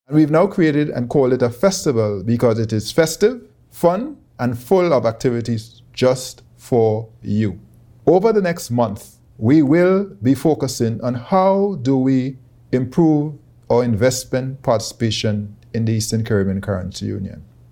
It is now called “FIM Festival” and ECCB’s Governor, Timothy Antoine, explains.
ECCB’s Governor, Timothy Antoine.